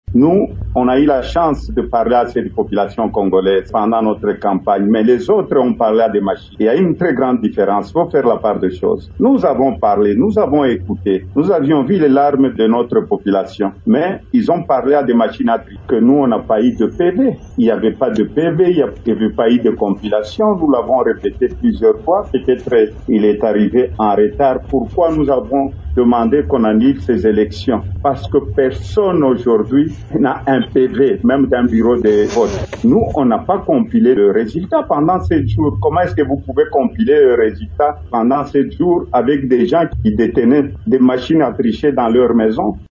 Ils ont réaffirmé leur position lors d’une conférence de presse conjointe tenue, jeudi 18 janvier, à Kinshasa.